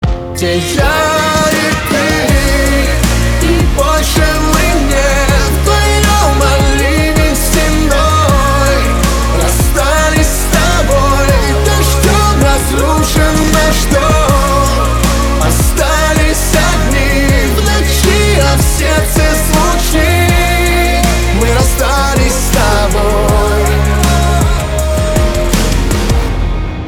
Поп Романтические